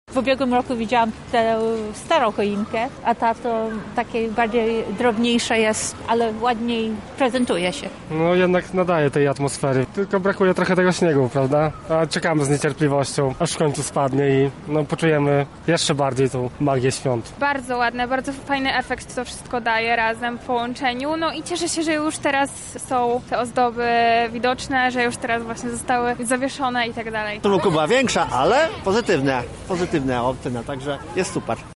Sonda